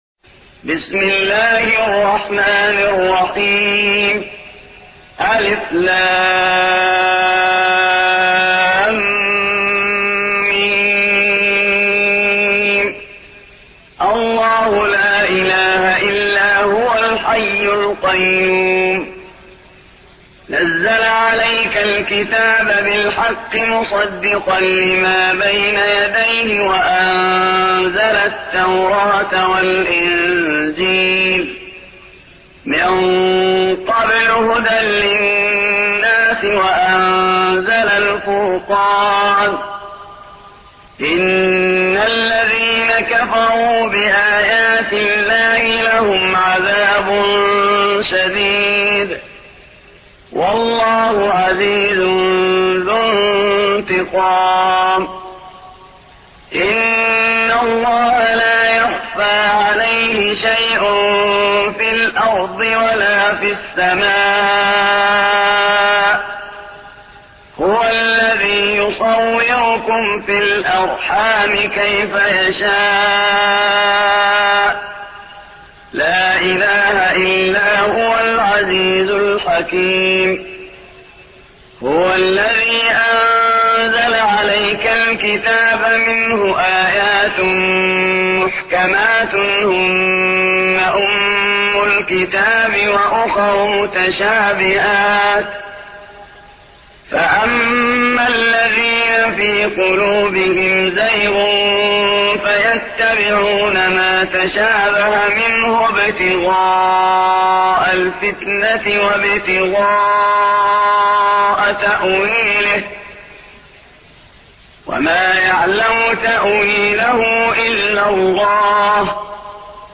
3. سورة آل عمران / القارئ